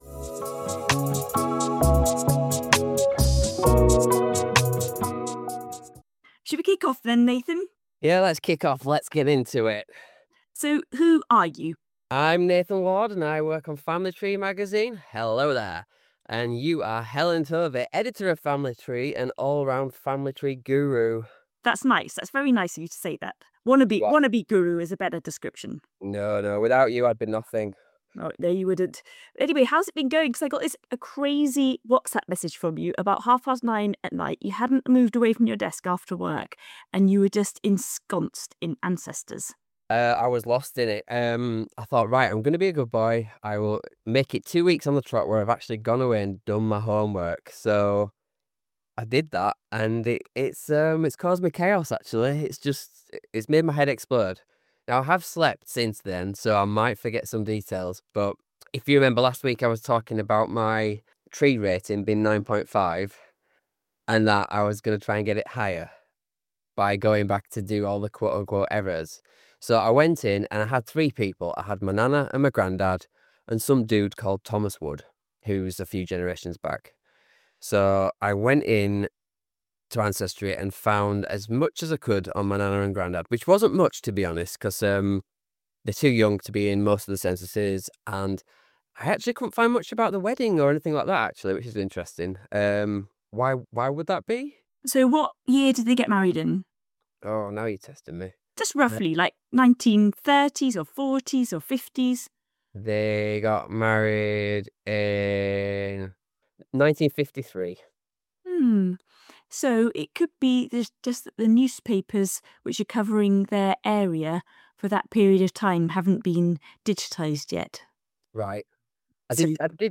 Whether you're new to genealogy or a seasoned researcher, you'll enjoy this lively conversation full of helpful ideas for unpicking even the knottiest branches of your family tree.